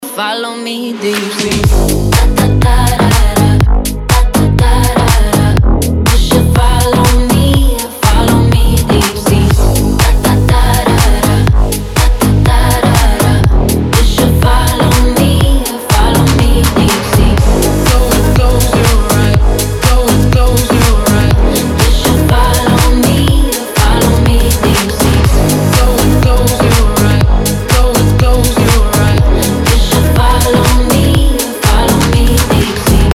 • Качество: 320, Stereo
Electropop
Style: Dance/Electro Pop